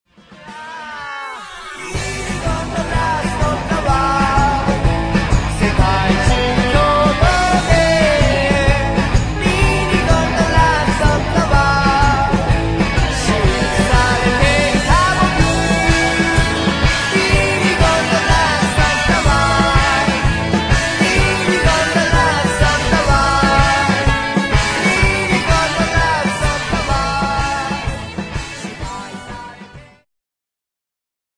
ジャンル Progressive
ギターフューチュア
ボーカルフューチュア